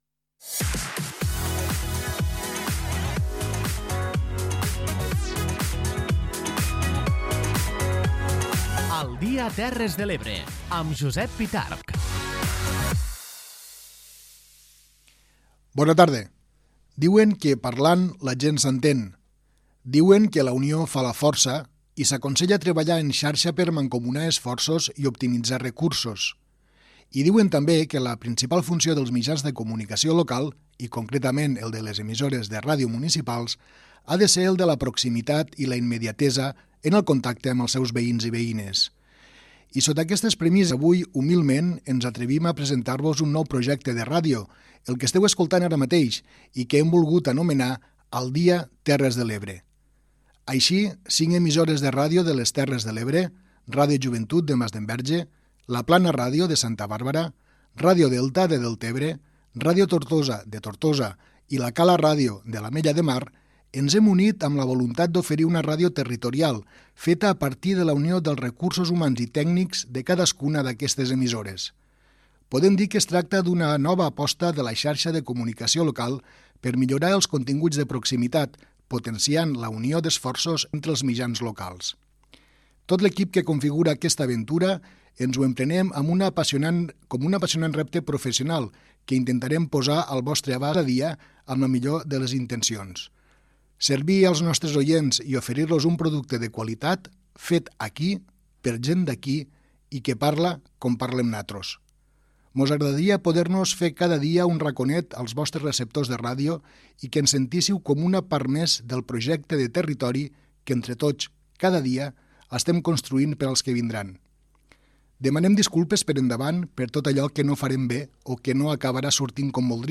Fragment de la primera emissió d'un informatiu conjunt emès per Ràdio Joventut de Masdenverge, La Plana Ràdio de Santa Bàrbara, Ràdio Delta de Deltebre, Ràdio Tortosa i La Cala Ràdio de L'Ametlla de Mar . Objectius del programa, indicatiu i sumari de continguts.
Info-entreteniment